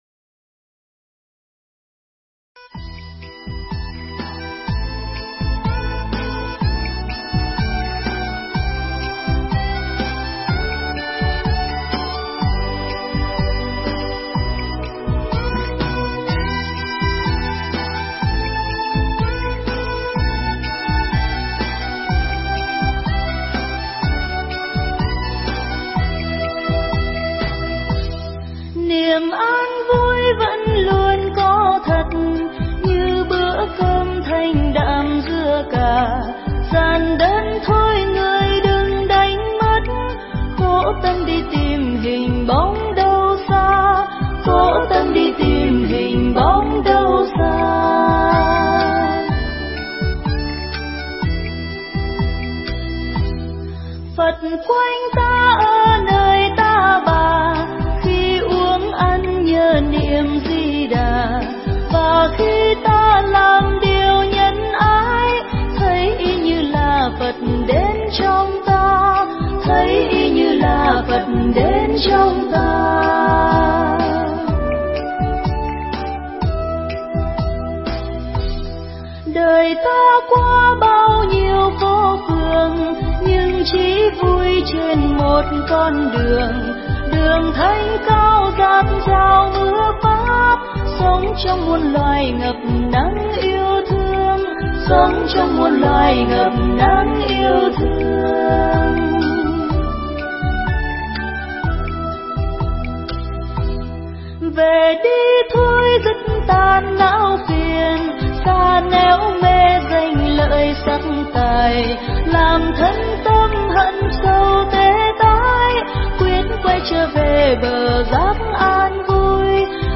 Mp3 Thuyết Pháp Đời Sống Và Việc Làm Cao Quý Của Người Phật Tử
giảng tại Chùa Thanh Quang, Huyên Lấp Vò, Đồng Tháp